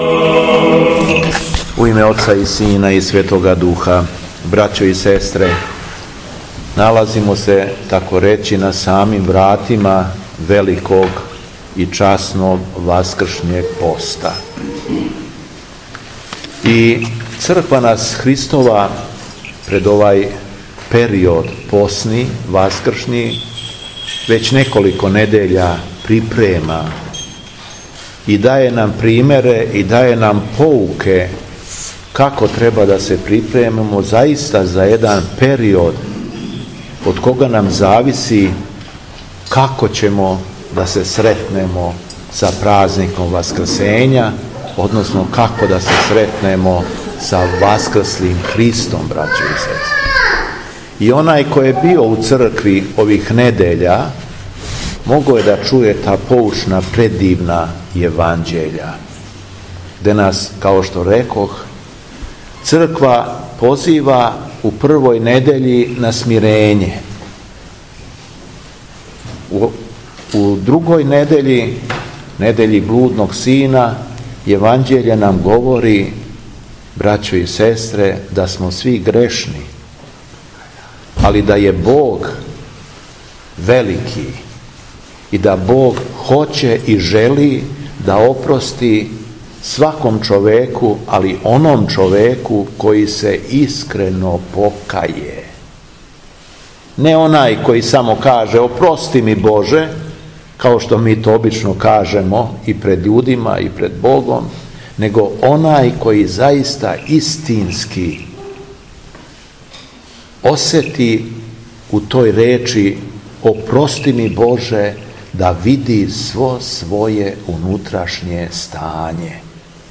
Беседа Епископа шумадијског Г. Јована
У месопусну недељу, 23. фебруара 2020. године, када наша Црква молитвено прославља светог свештеномученика Харалампија, Његово Преосвештенство Епископ шумадијски Г. Јован началствовао је евхаристијским сабрањем у Сопоту.